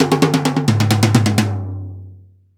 Index of /90_sSampleCDs/Roland - Rhythm Section/TOM_Rolls & FX/TOM_Tom Rolls
TOM TOM R03R.wav